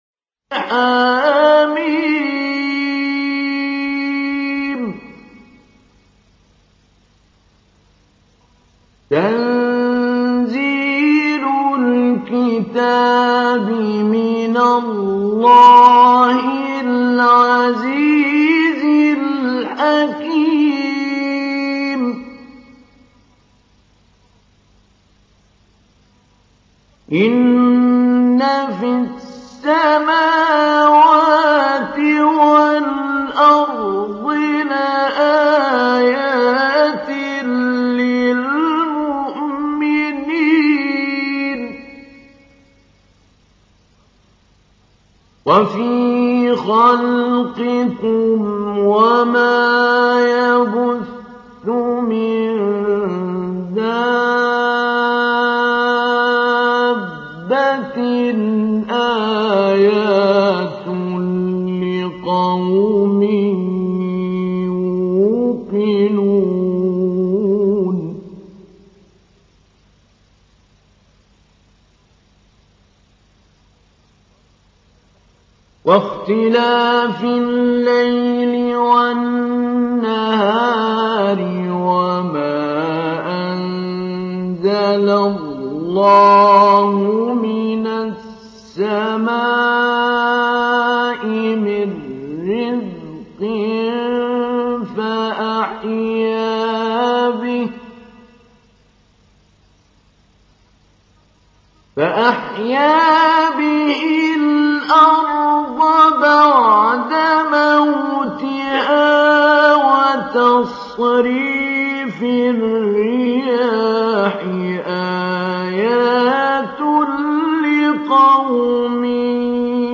تحميل : 45. سورة الجاثية / القارئ محمود علي البنا / القرآن الكريم / موقع يا حسين